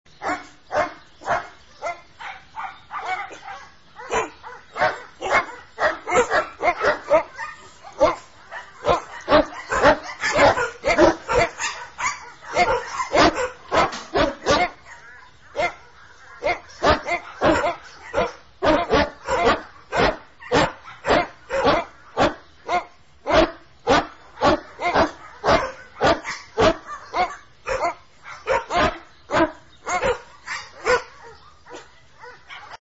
3D spatial surround sound "Dogs barking"
3D Spatial Sounds